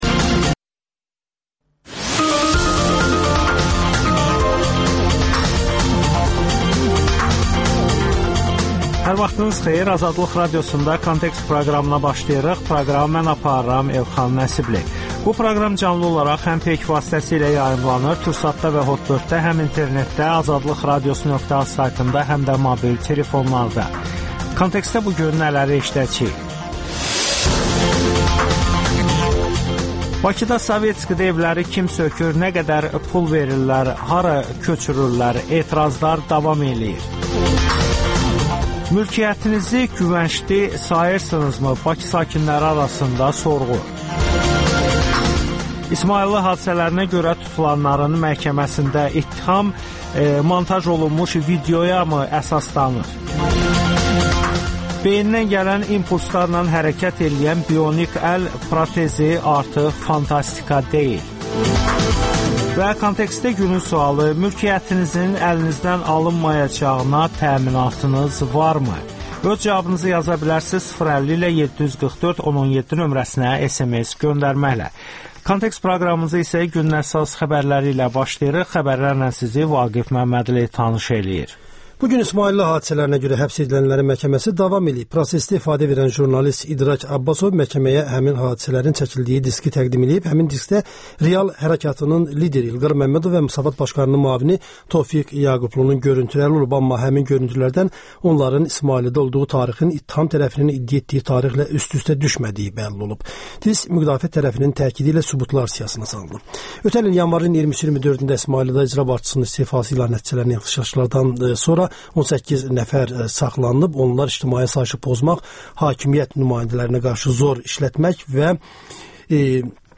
Verilən kompensasiyanın nə dərəcə qaneedici olduğunu Bakı sakinlərindən soruşduq.